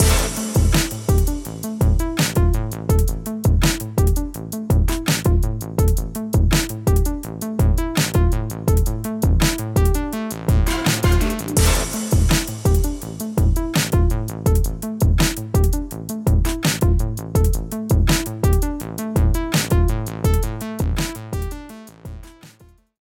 PROG_EQP1A_BRIGHT_MIX.mp3